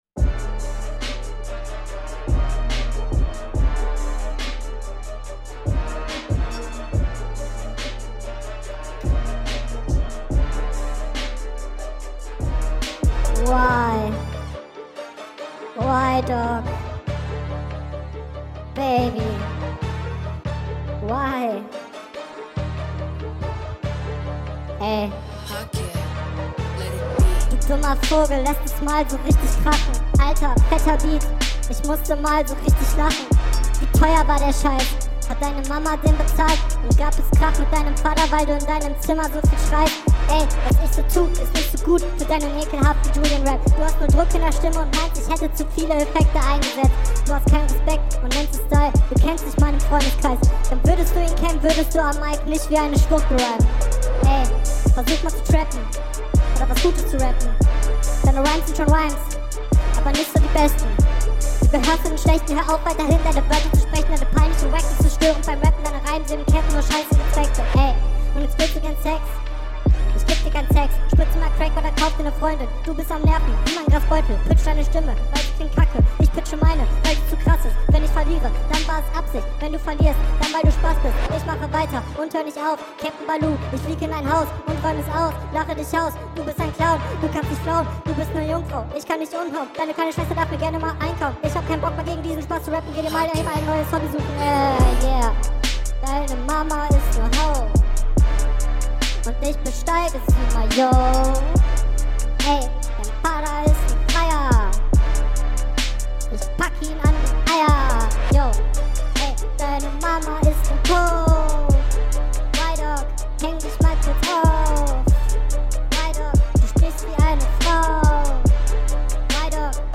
Hatte gar nicht schlechte punches, dafür, dass es so freestyle mäßig war hah Skill …